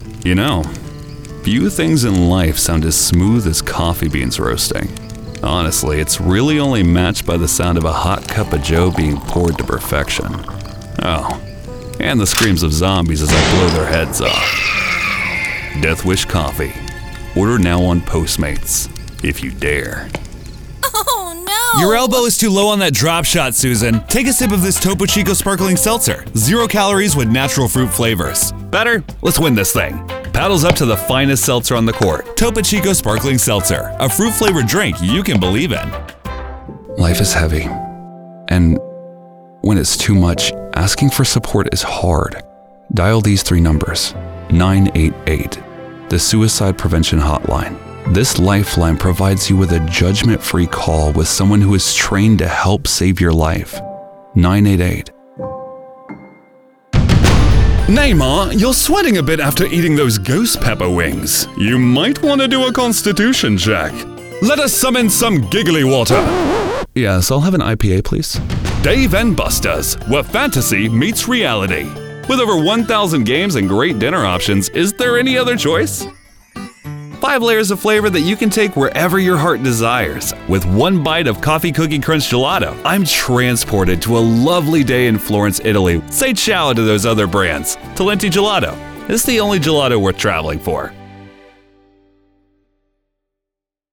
Llamativo, Versátil, Cálida, Amable, Empresarial
Comercial